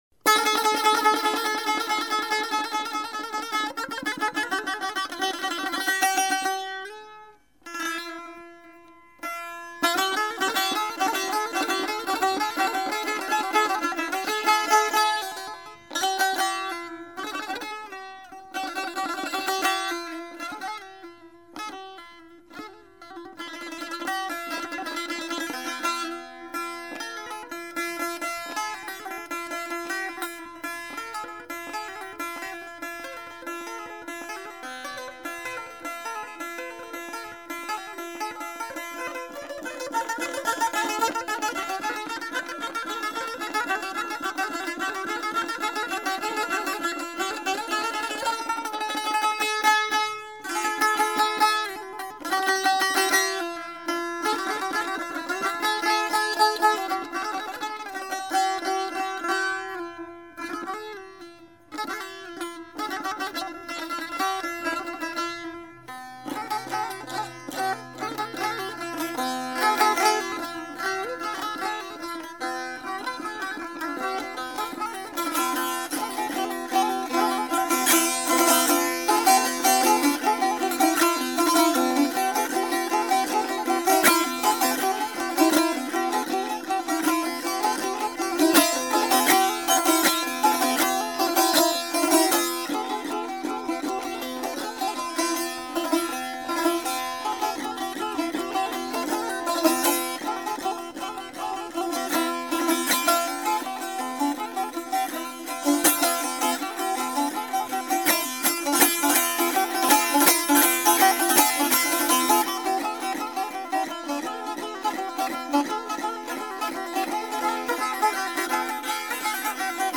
آشیق